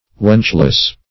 Search Result for " wenchless" : The Collaborative International Dictionary of English v.0.48: Wenchless \Wench"less\, a. Being without a wench.